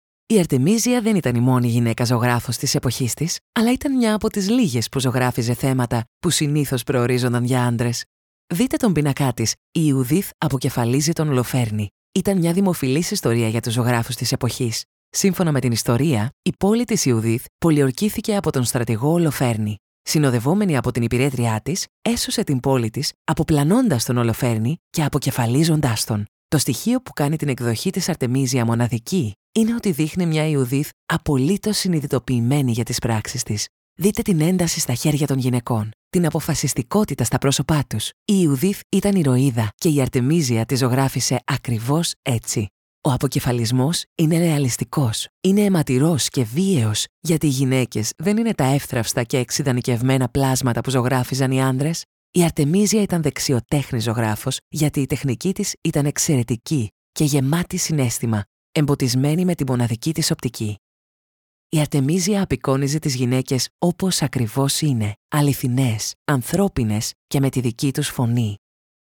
Female
Approachable, Assured, Authoritative, Character, Children, Confident, Conversational, Corporate, Deep, Energetic, Engaging, Friendly, Natural, Reassuring, Smooth, Versatile, Warm
Microphone: MKH 416 Sennheiser Shotgun
Audio equipment: Professionally sound-proofed home studio room, RME Fireface UCX II, Audio-Technica ATH M50x Headphones, Kali Audio studio monitors